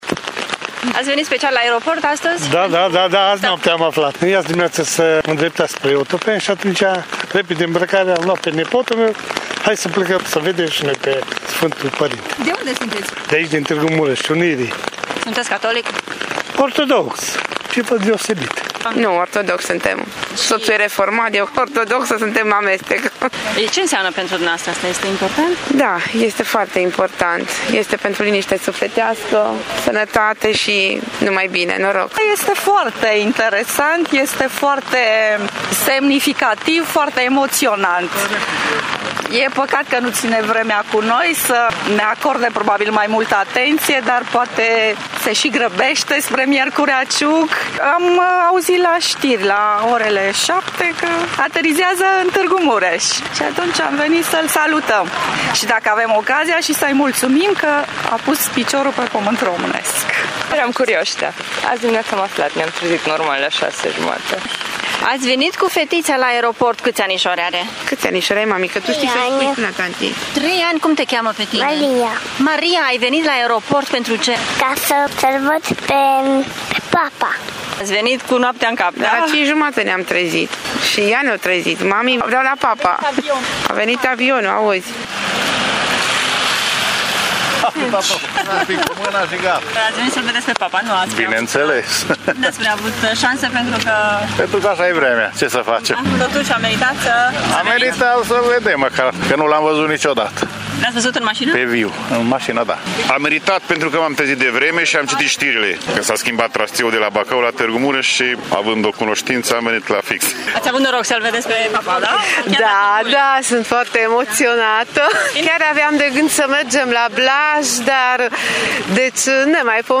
Zeci de târgumureșeni l-au așteptat ore întregi în ploaie pe Papa Francisc la aeroport